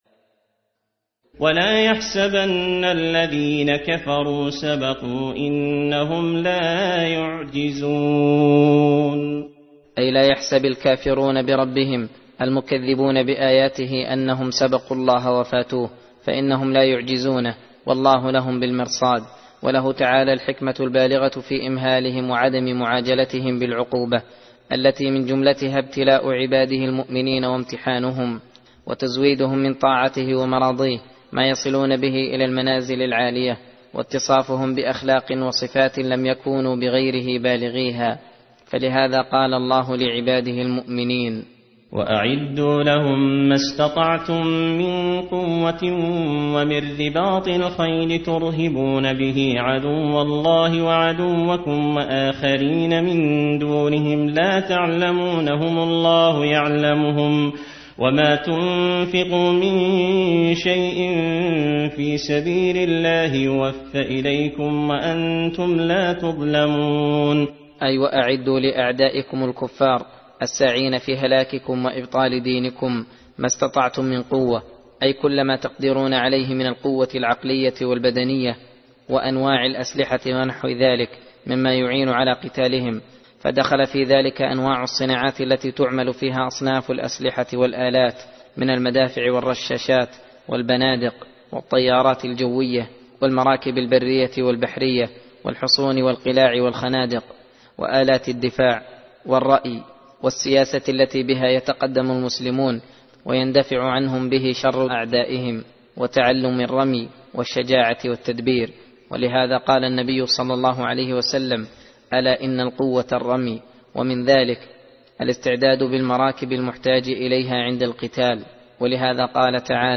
درس (15) : تفسير سورة الأنفال (59-75)